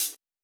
Closed Hats
Hat (10).wav